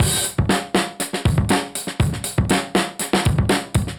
Index of /musicradar/dusty-funk-samples/Beats/120bpm/Alt Sound